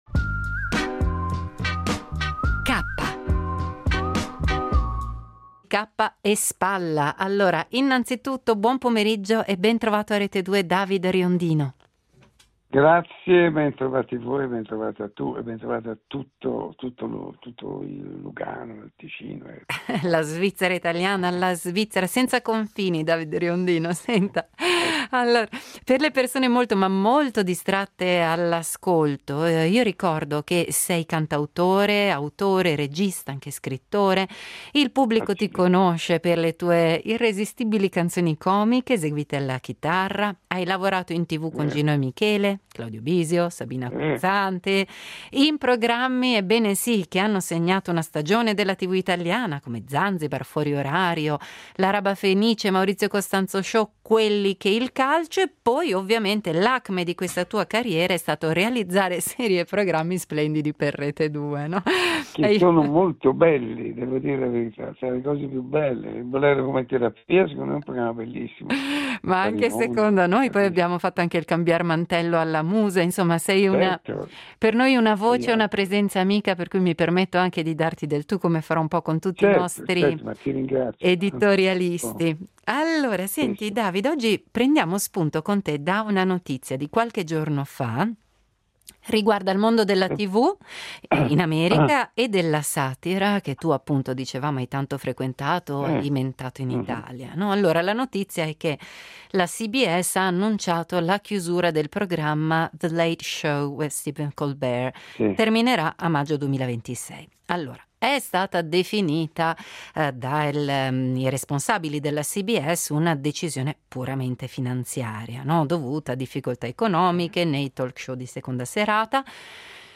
L’editoriale del giorno firmato da Davide Riondino